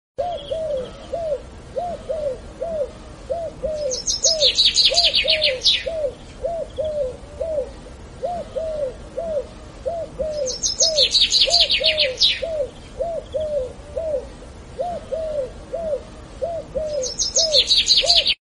Old is gold beautiful village sound effects free download